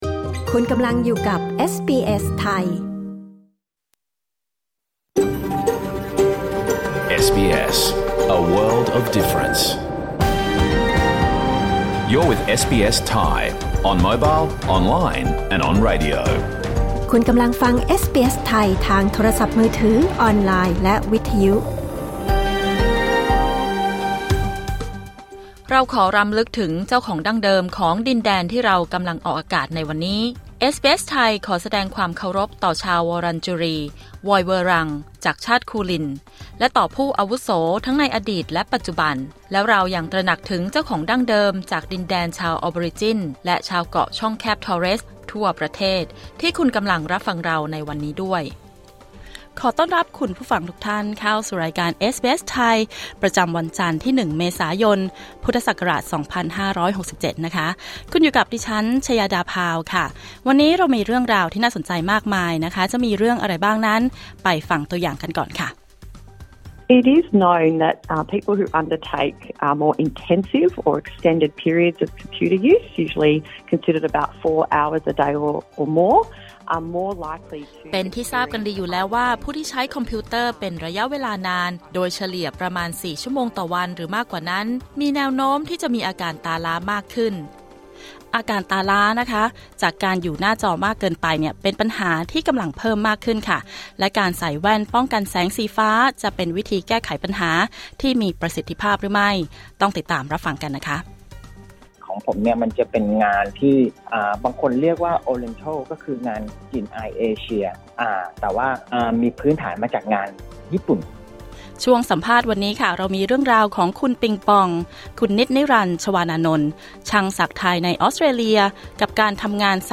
รายการสด 1 เมษายน 2567